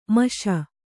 ♪ maśa